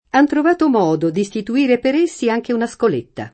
#n trov#to m0do d iStitu-&re per %SSi ajke una Skol%tta] (Pirandello); la Scoletta (o Scuola) del Santo, a Padova, sede dell’Arciconfraternita di sant’Antonio — sim. il cogn.